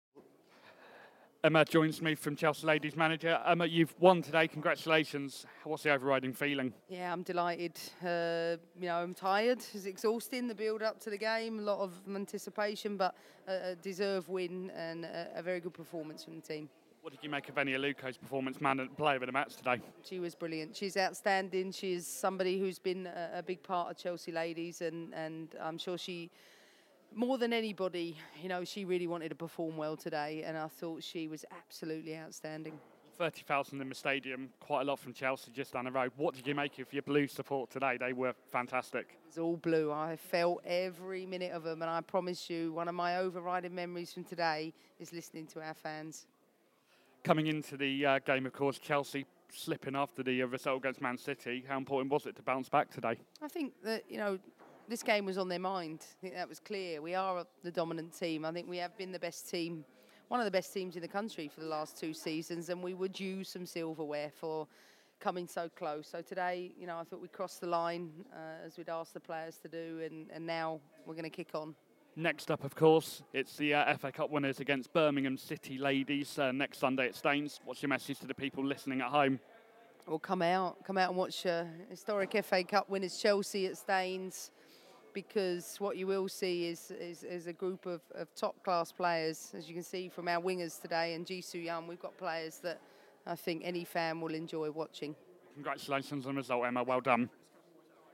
Emma Hayes interview after the fa cup final